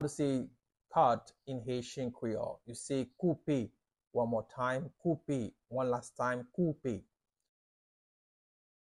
Listen to and watch “Koupe” pronunciation in Haitian Creole by a native Haitian  in the video below:
How-to-say-Cut-in-Haitian-Creole-Koupe-pronunciation-by-a-Haitian-Creole-teacher.mp3